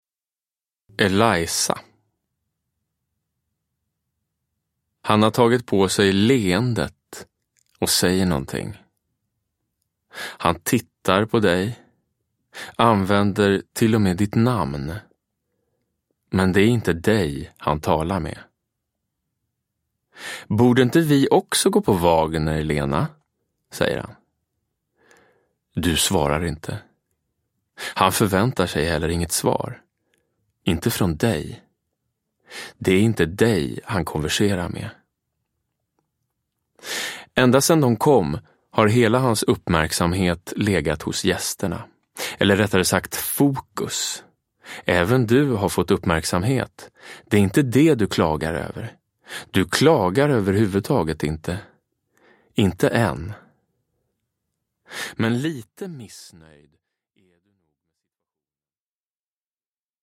Eliza – Ljudbok – Laddas ner
Uppläsare: Jonas Karlsson